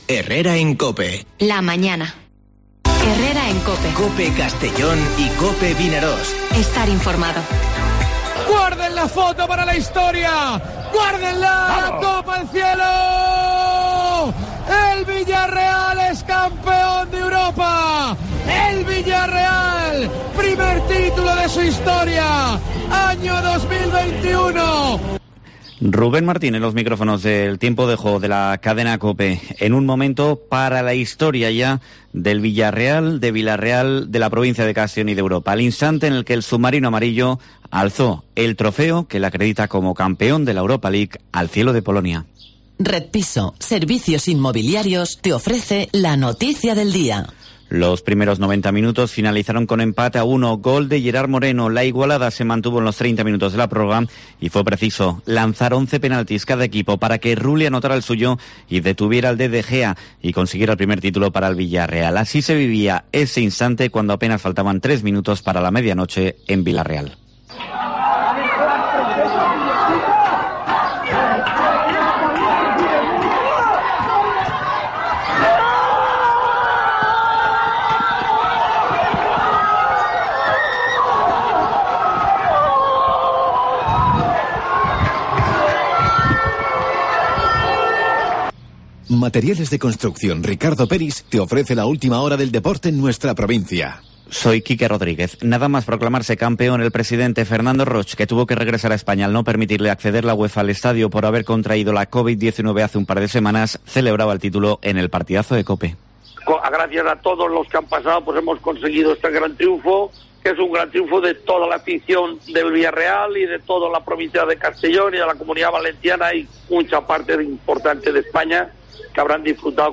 Informativo Herrera en COPE en la provincia de Castellón (27/05/2021)